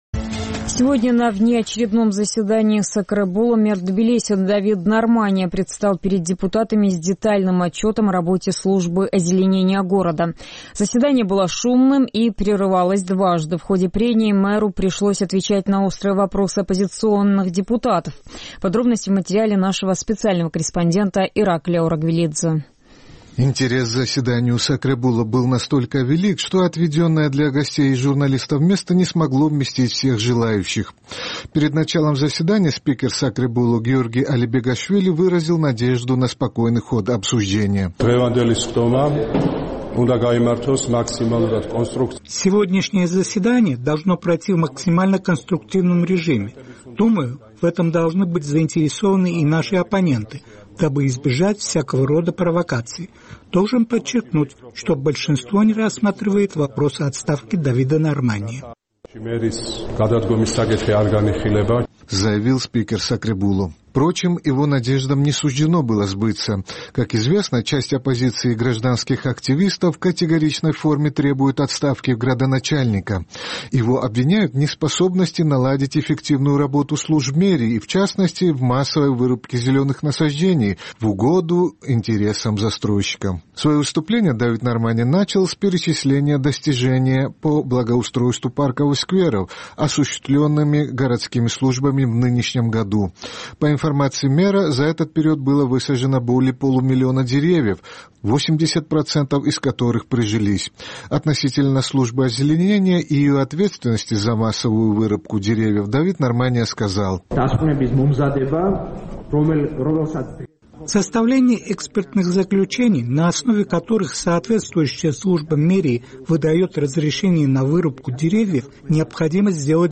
Сегодня на внеочередном заседании сакребуло мэр Тбилиси Давид Нармания предстал перед депутатами с детальным отчетом о работе Службы озеленения города. Заседание было шумным и прерывалось дважды.